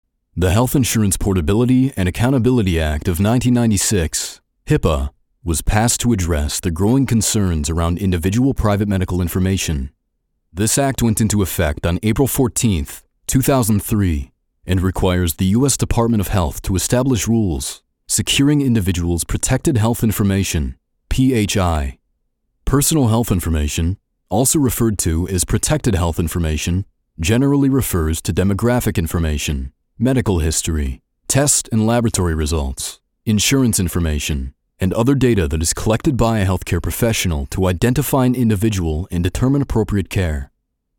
Voiceover Demos
Narration Sample
Broadcast-Quality Studio